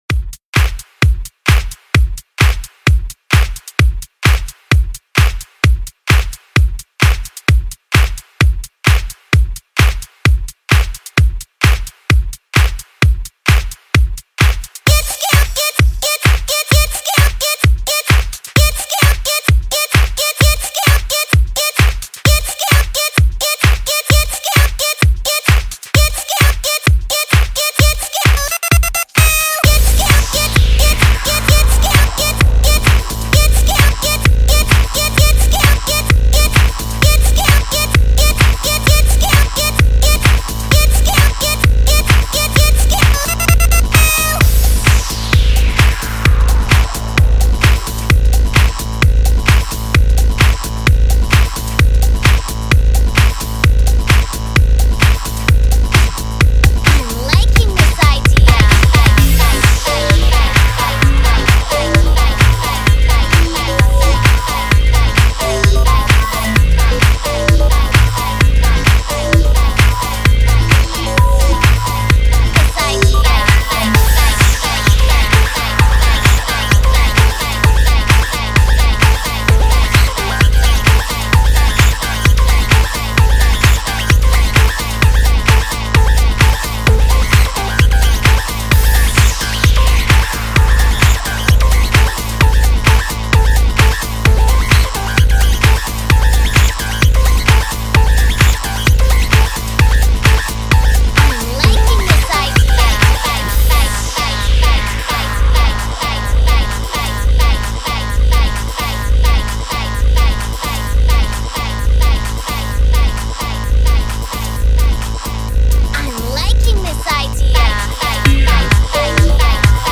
OLDSKOOL Elektro